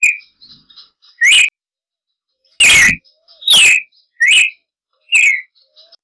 Paroaria coronata - Cardenal de copete rojo
cardenalcopeterojo.wav